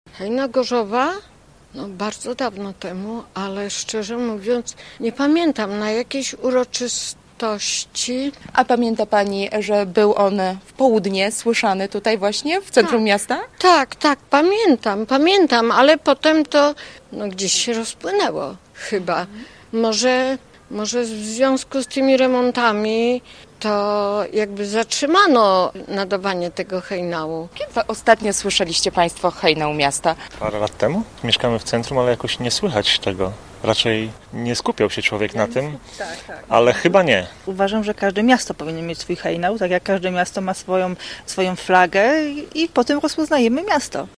– W południe odtwarzany był z budynku urzędu miasta, jednak od lat już go nie słychać – mówili gorzowianie: